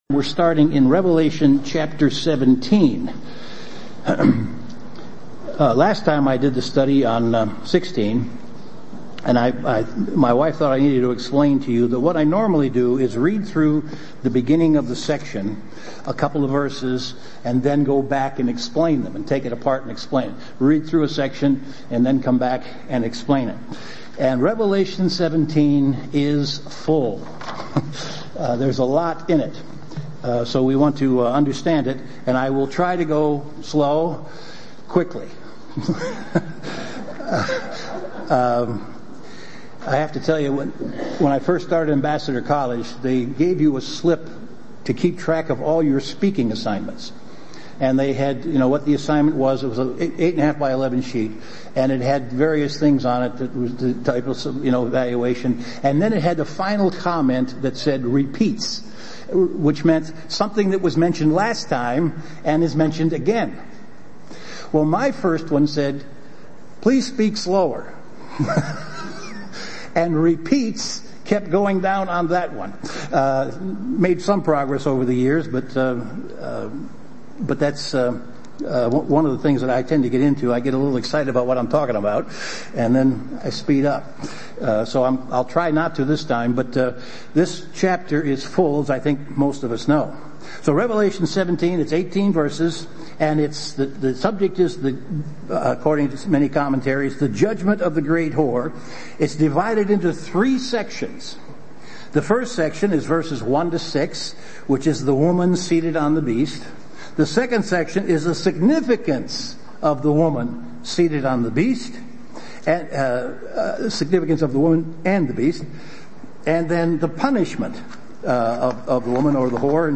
Given in Chicago, IL
UCG Sermon